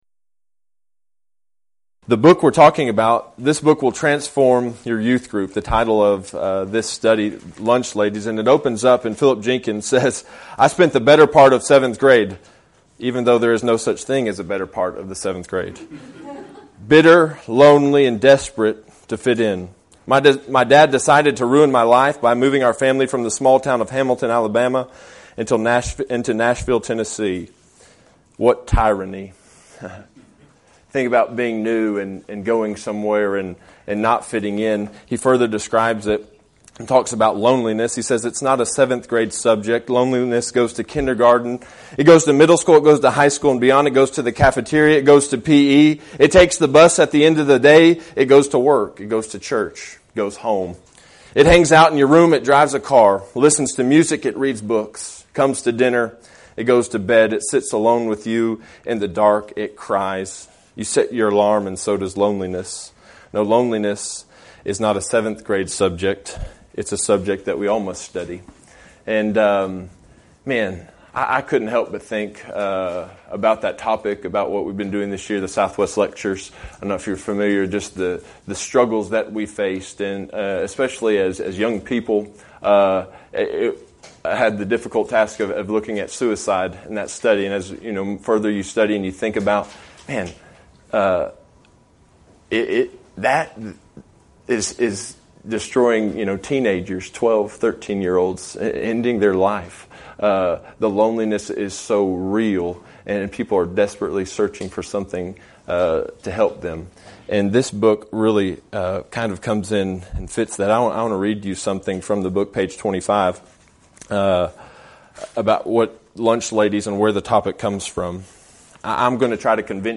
Event: 2015 Discipleship University
lecture